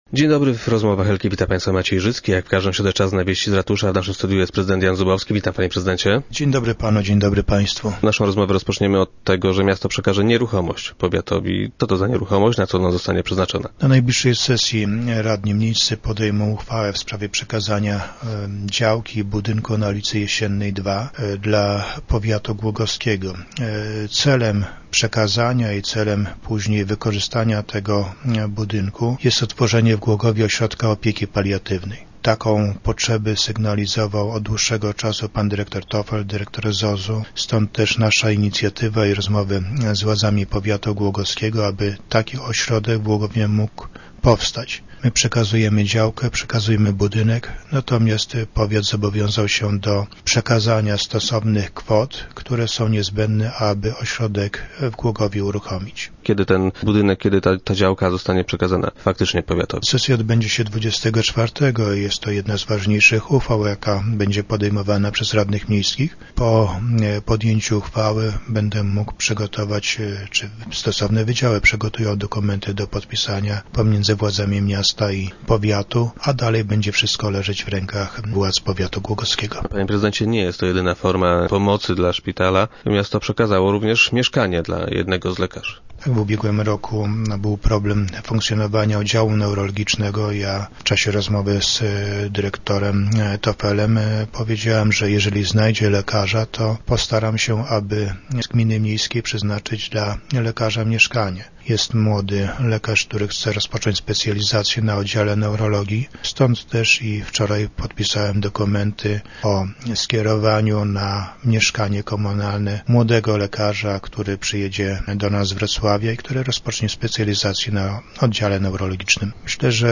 - Decyzję w tej sprawie miejscy radni podejmą podczas najbliżej sesji - informuje prezydent Jan Zubowski, który był dziś gościem Rozmów Elki.